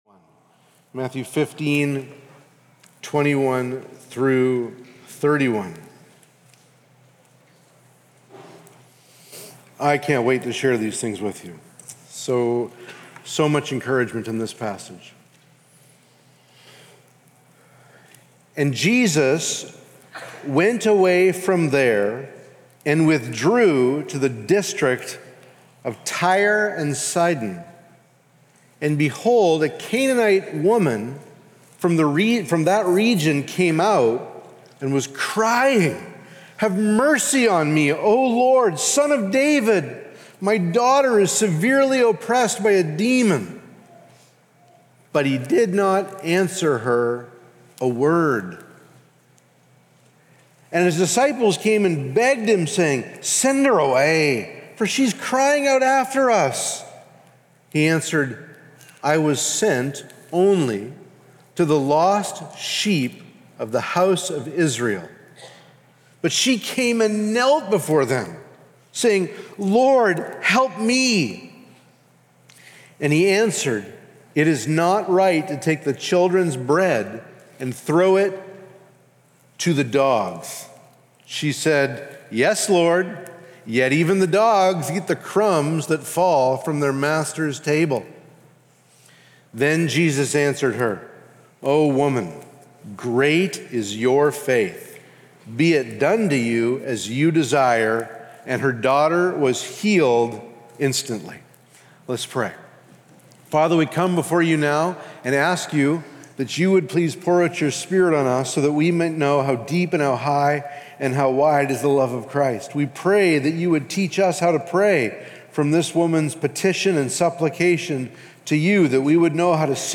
Sermons | Immanuel Baptist Church